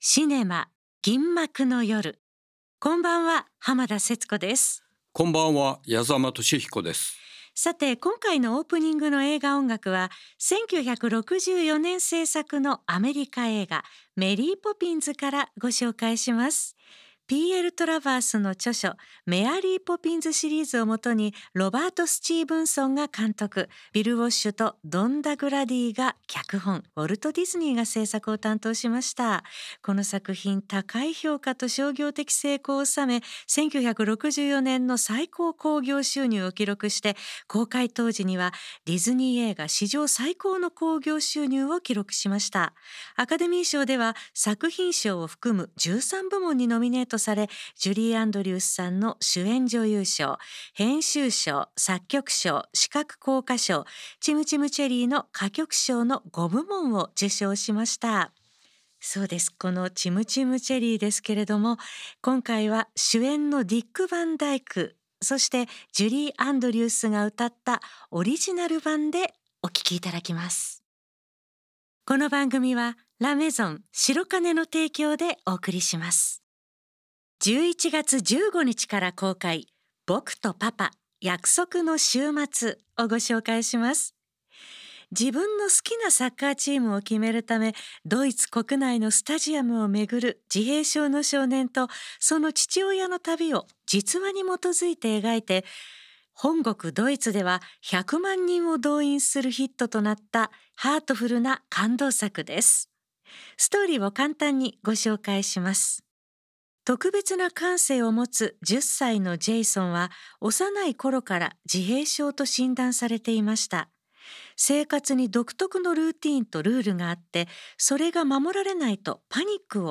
最新の映画情報と過去の名作映画を音楽と共に紹介する30分。